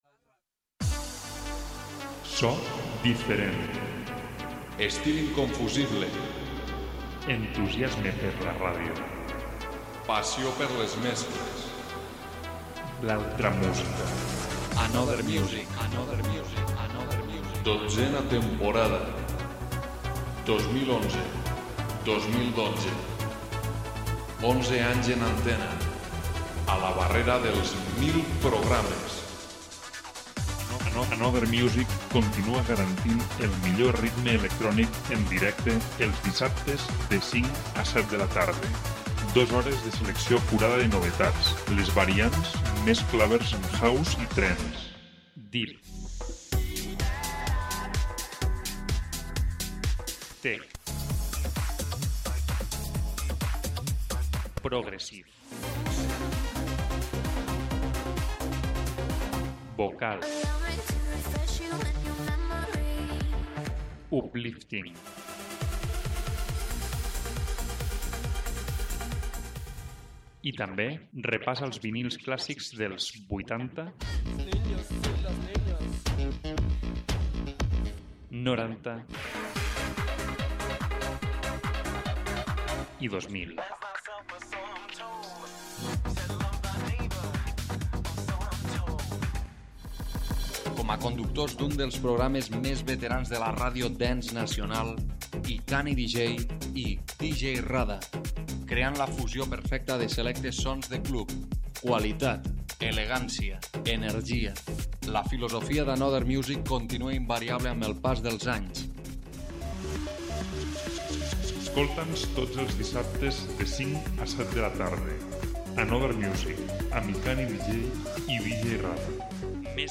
Deep/Soul/Tech/vocal-House